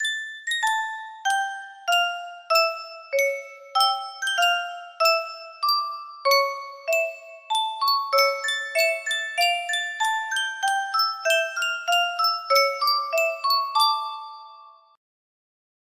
Yunsheng Music Box - Beethoven Bagatelle Op. 119 No. 1 1489 music box melody
Full range 60